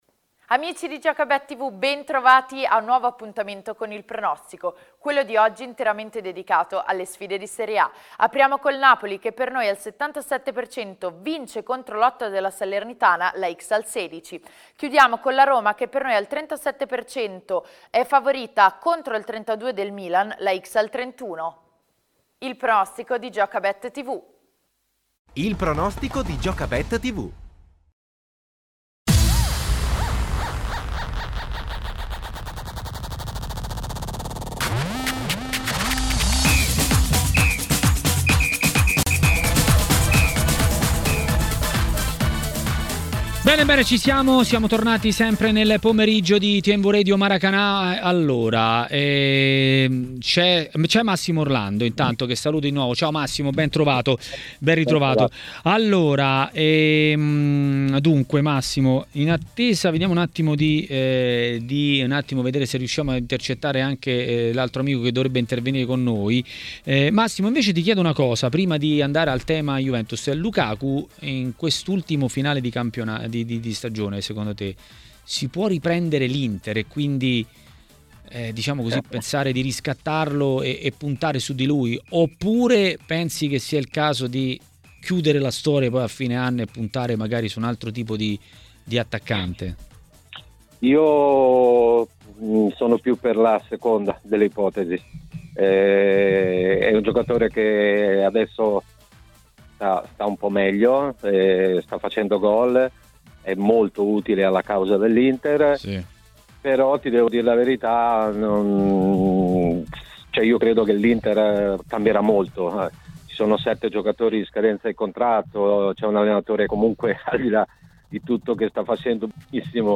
A commentare le notizie del giorno a Maracanà, nel pomeriggio di TMW Radio, è stato l'ex calciatore Massimo Orlando.